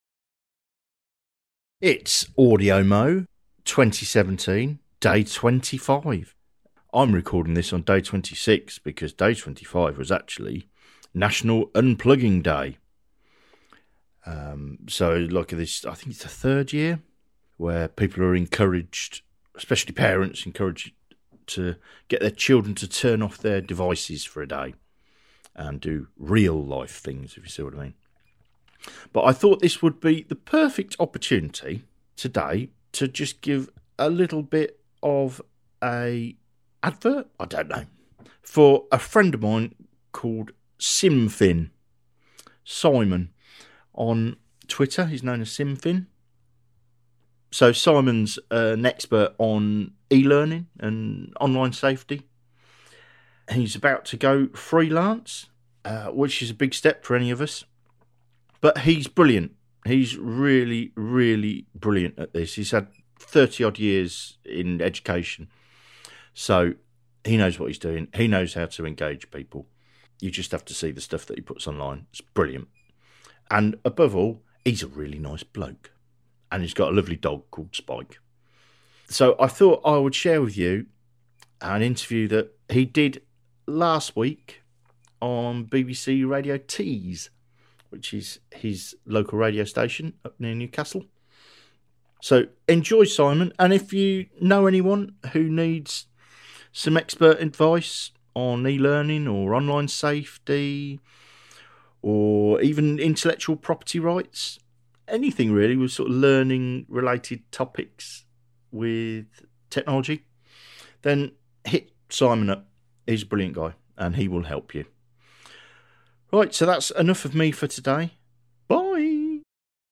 talking about e-safety on BBC Tees.